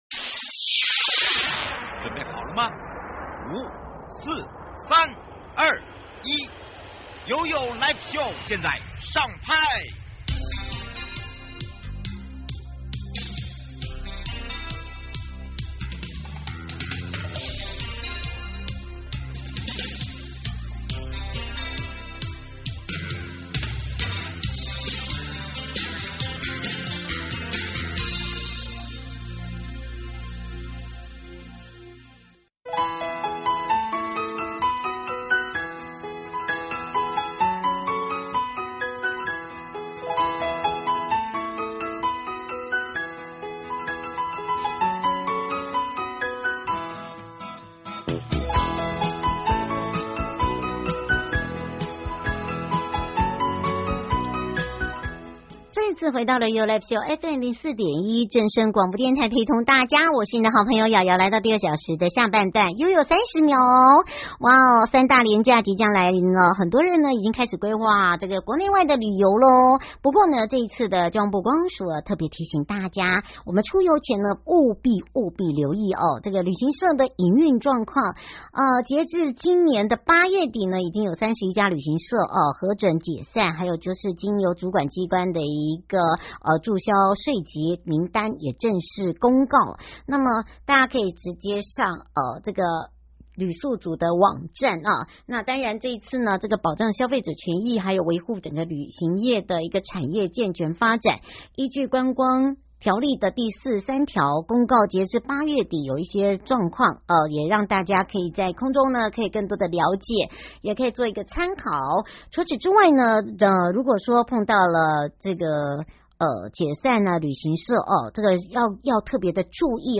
九大鄒族部落齊聚 展現原民文化魅力∼GO∼ 受訪者：